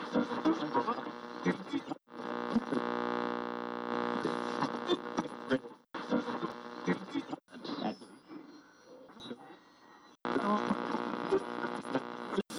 rdo_sound_radio01.wav